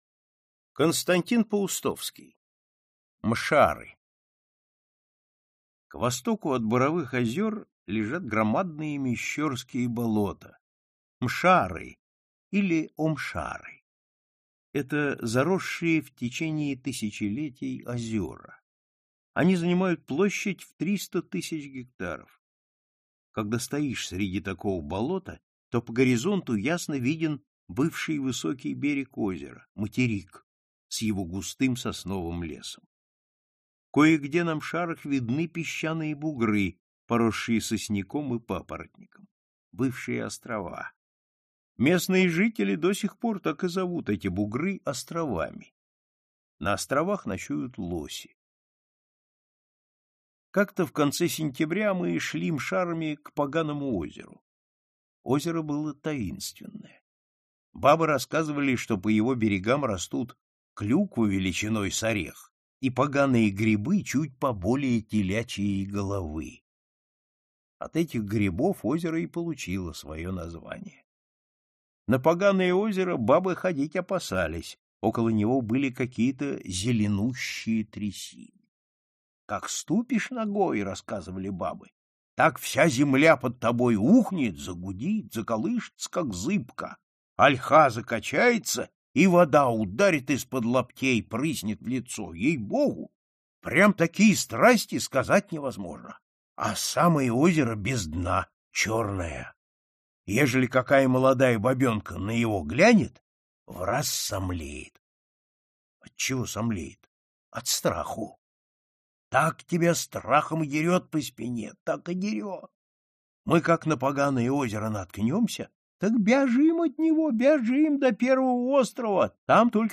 Aудиокнига Классика русского рассказа № 16 Автор Сборник Читает аудиокнигу Всеволод Кузнецов.